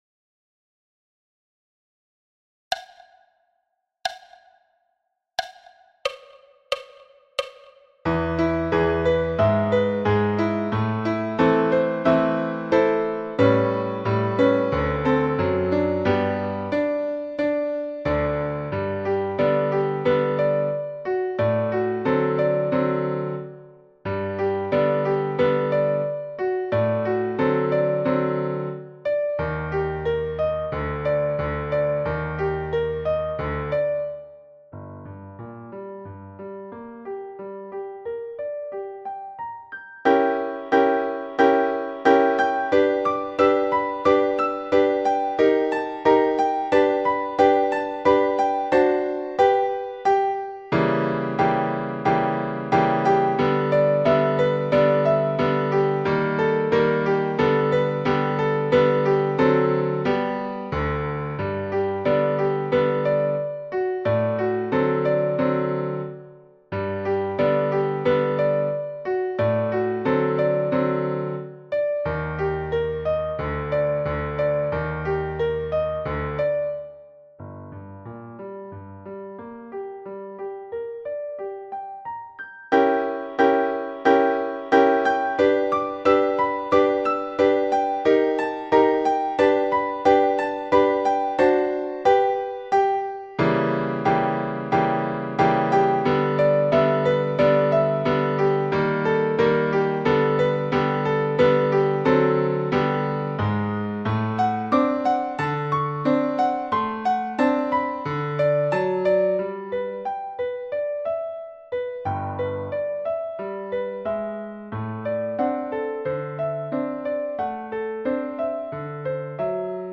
Mapple leaf rag – piano à 90 bpm
Mapple-leaf-rag-piano-a-90-bpm.mp3